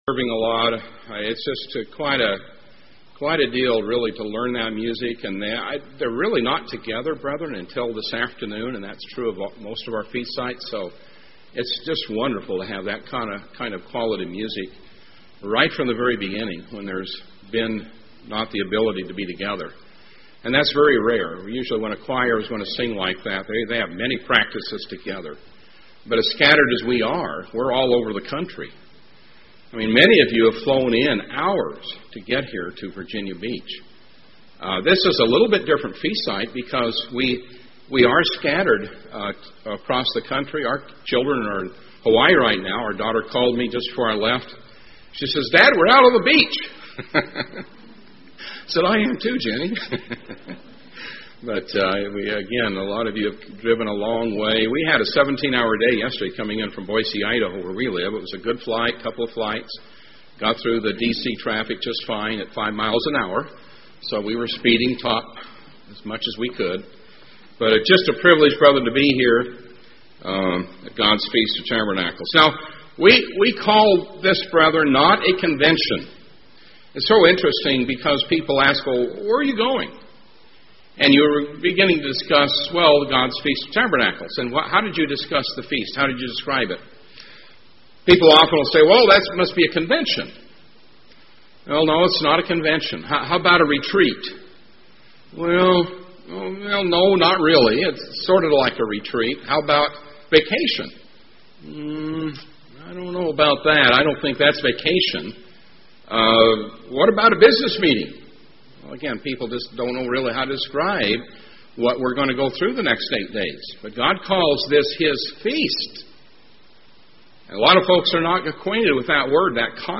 This sermon was given at the Virginia Beach, Virginia 2011 Feast site.